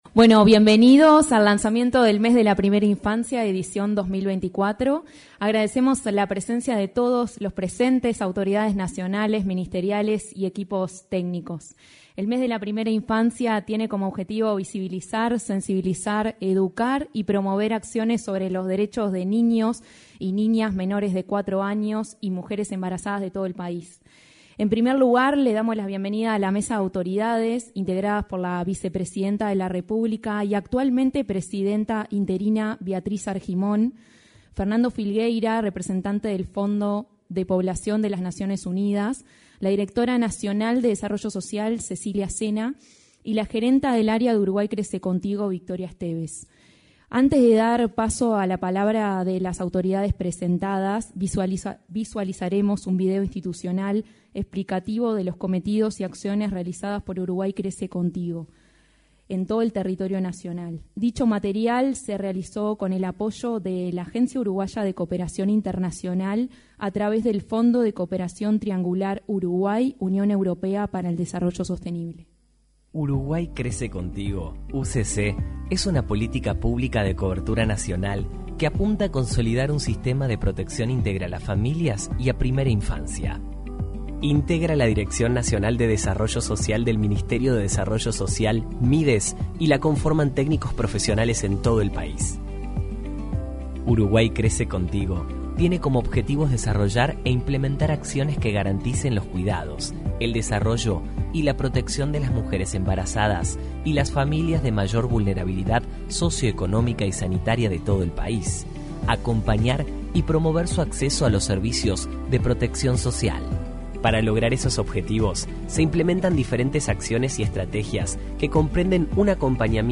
Lanzamiento del mes de la Primera Infancia 02/05/2024 Compartir Facebook Twitter Copiar enlace WhatsApp LinkedIn El Ministerio de Desarrollo Social, a través de la Dirección Nacional de Desarrollo Social, llevó a cabo el lanzamiento del Mes de la Primera Infancia en el salón de actos de Torre Ejecutiva.